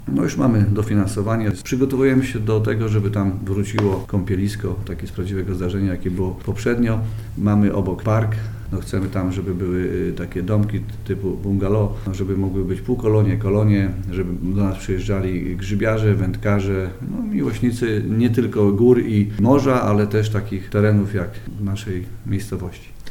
– To nie koniec planów i pomysłów na przyciągnięcie ludzi nad zalew – zaznacza burmistrz: